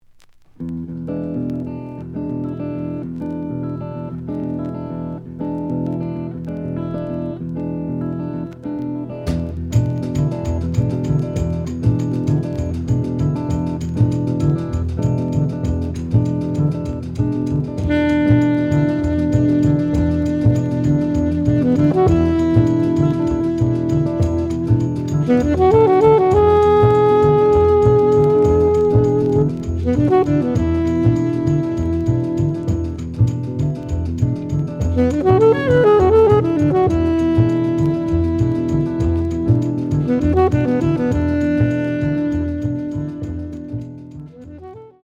The audio sample is recorded from the actual item.
●Genre: Modern Jazz